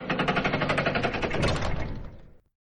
Door2Open2.ogg